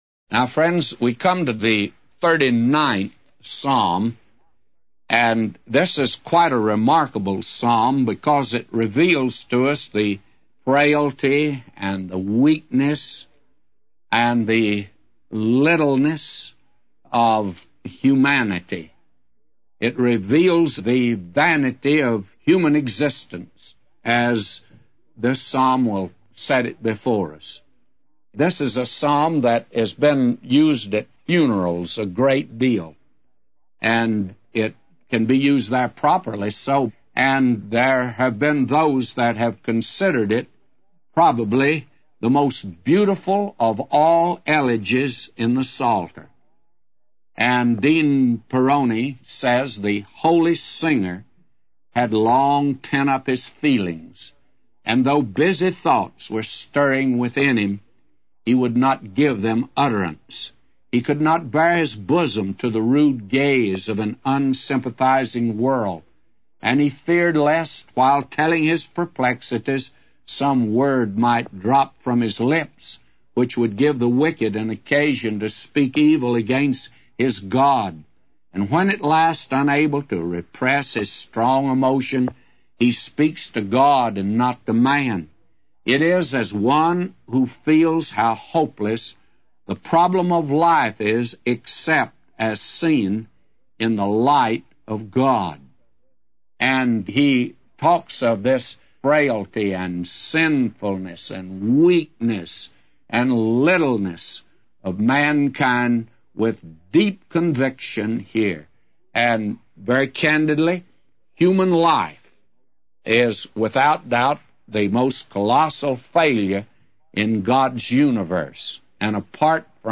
A Commentary By J Vernon MCgee For Psalms 39:1-999